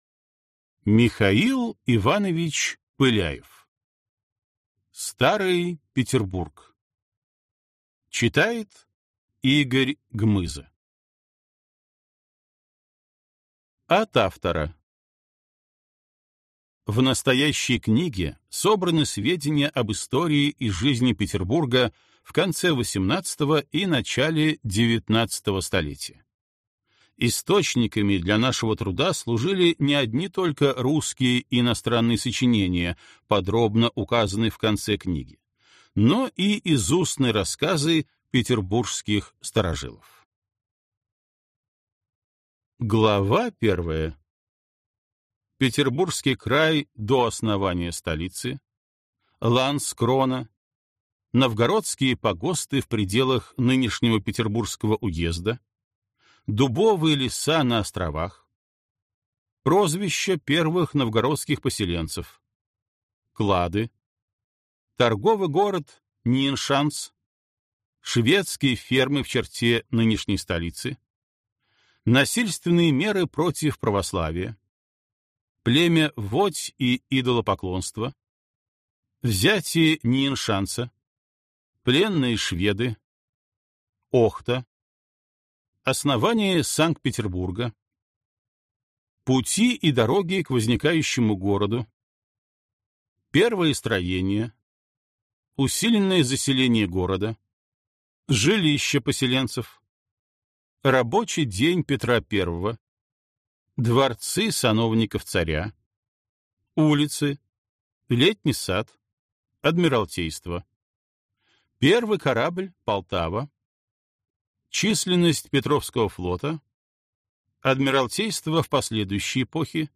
Аудиокнига Старый Петербург | Библиотека аудиокниг